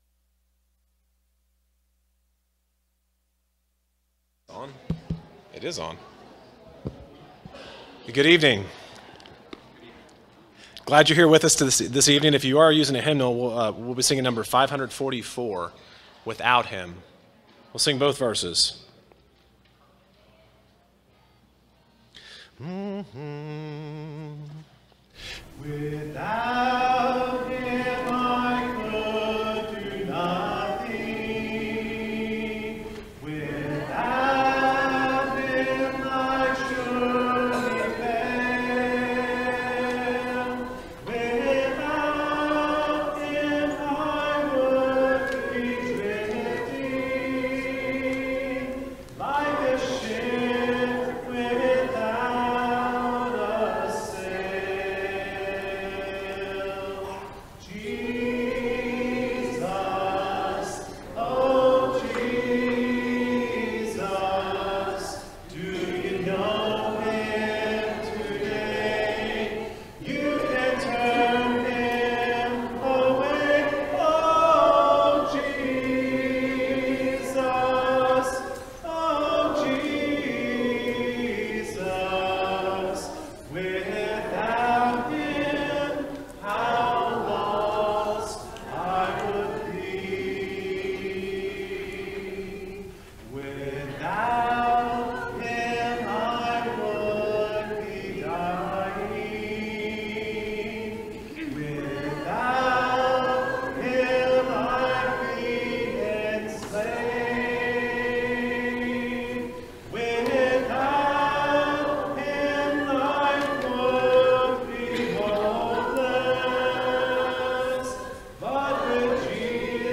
Colossians 4:6, English Standard Version Series: Sunday PM Service